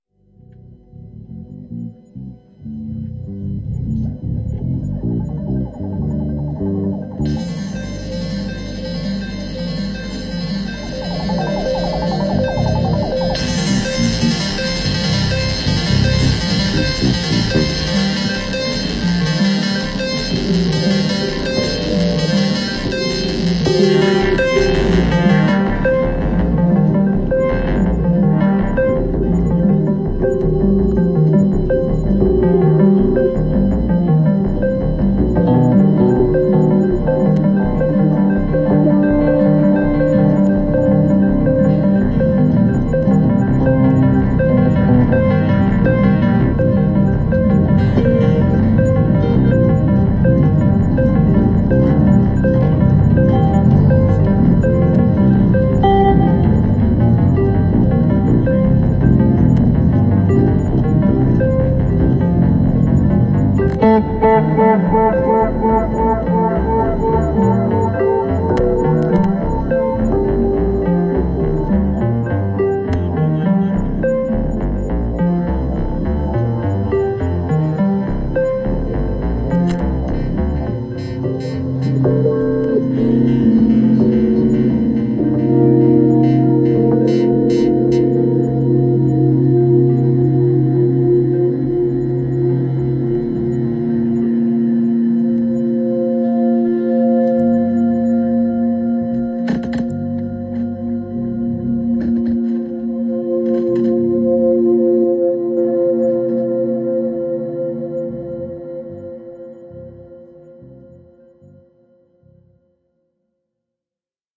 Recorded live @ Riserva San Settimio (Palazzo Arcevia)
voice + natural sound base noise